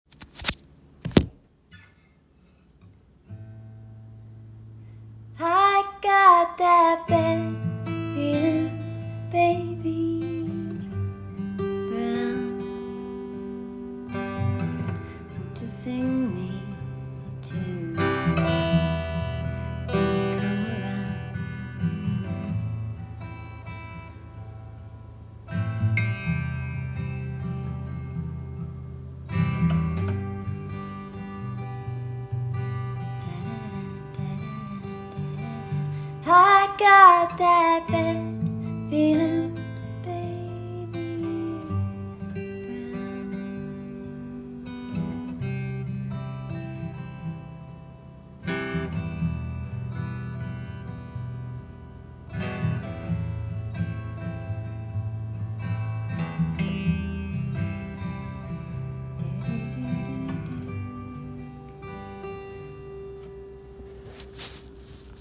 a piece of a song written june 2024